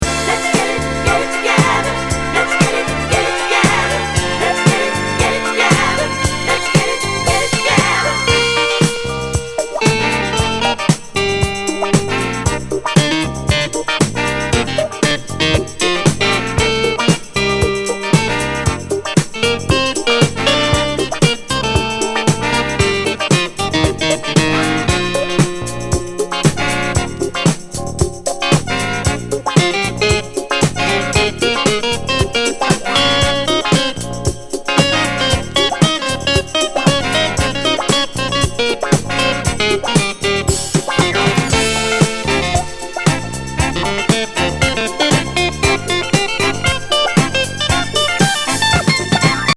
ナイス・ミディアム・イタロ・ブギー♪